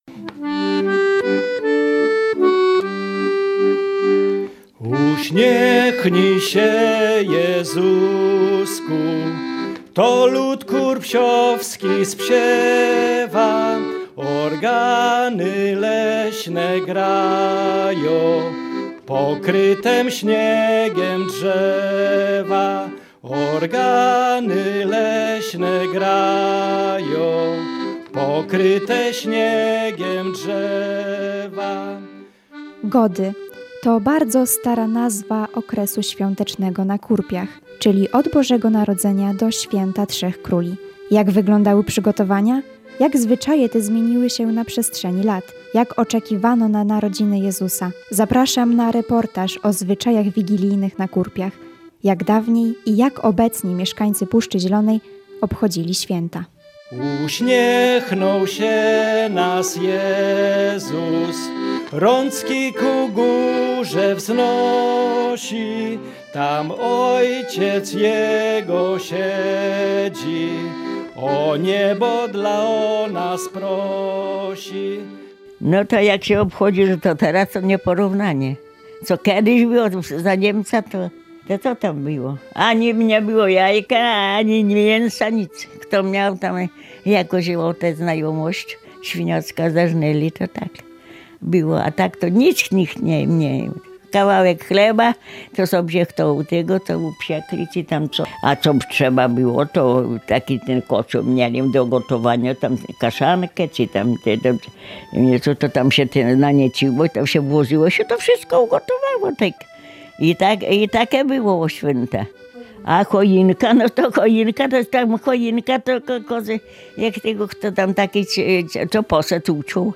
Swieta-na-Kurpiach-Reportaz.mp3